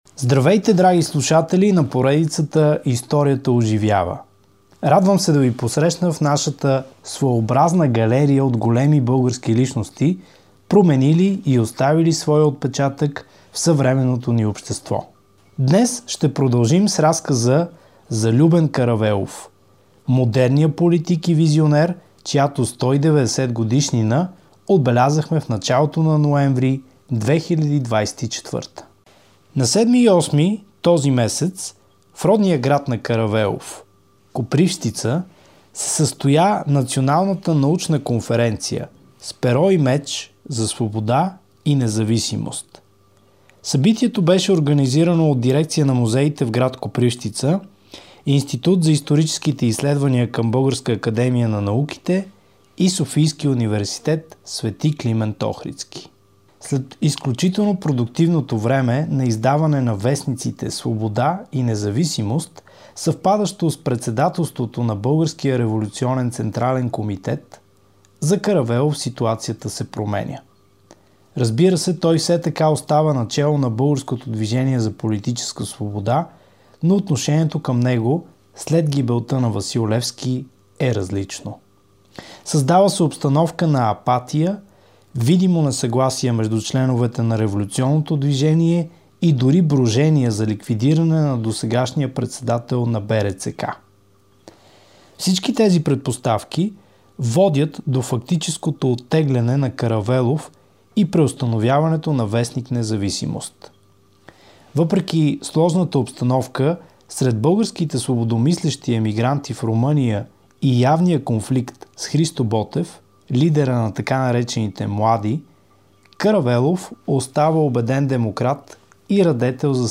В епизод първи, посветен на Любен Каравелов, отбелязахме 190 години от рождението му, а в този брой продължаваме със записите от Националната научна конференция.